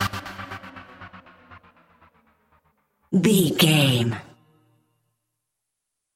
Aeolian/Minor
drum machine
synthesiser
electric piano
Eurodance